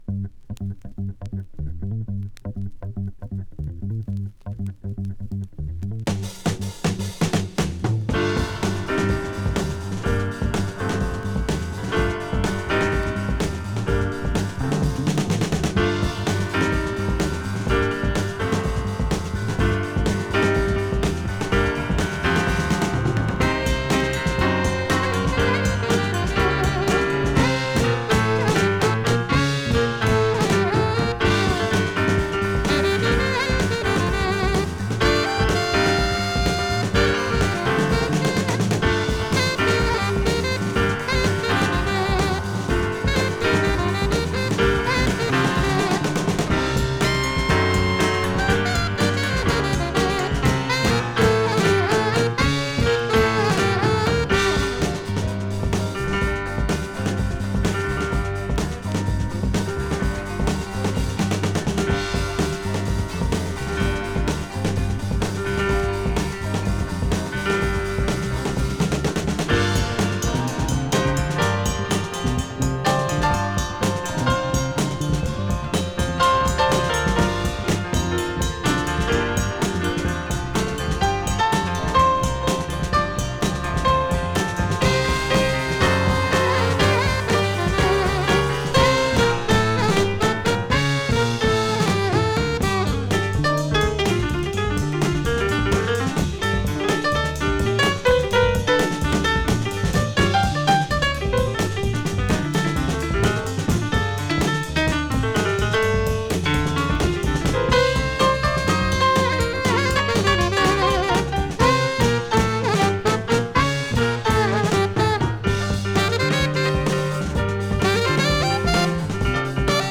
Psychedelic Brass Rock!
疾走感のあるインスト・ブラスロック
【JAZZ ROCK】【PSYCHEDELIC】